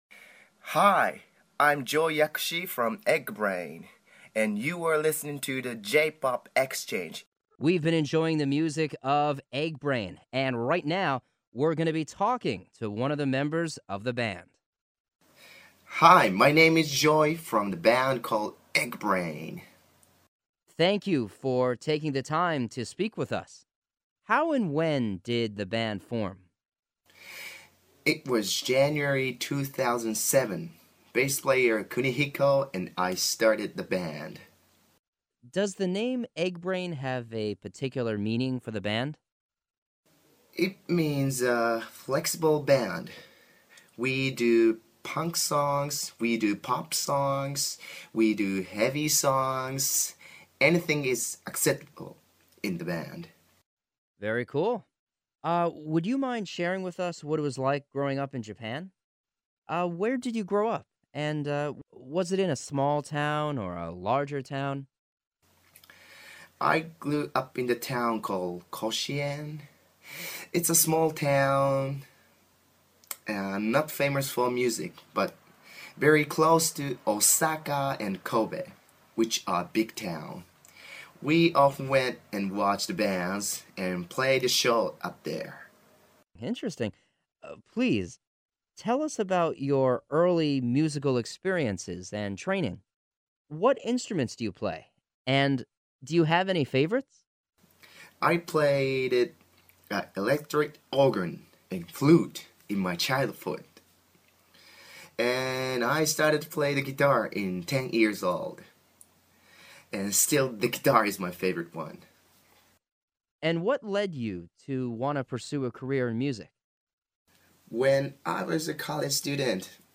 Exclusive Radio Interview